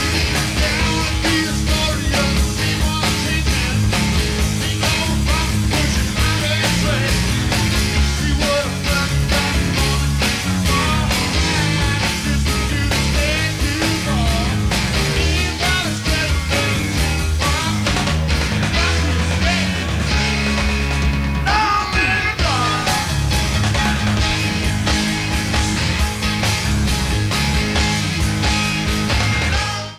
Pre-FM Radio Station Reels
Needs remastering.